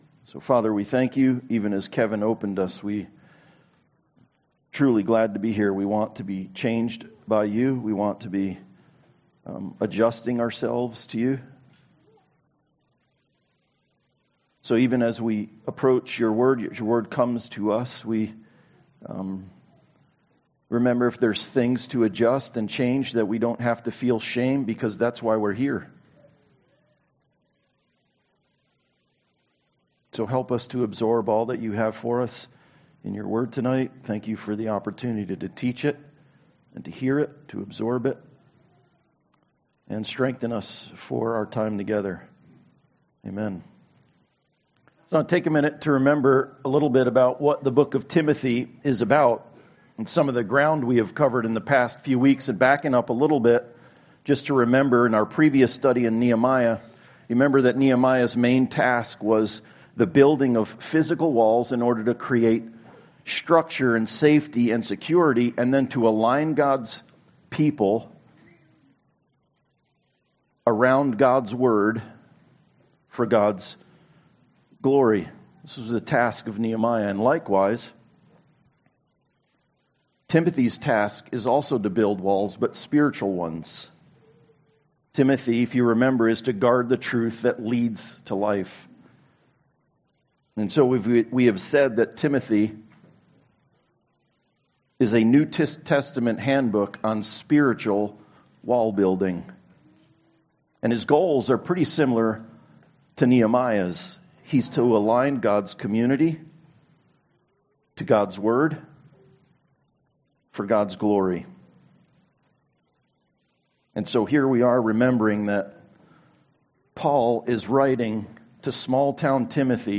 1 Timothy 3:1-7 Service Type: Sunday Service Timothy’s task is to build spiritual walls.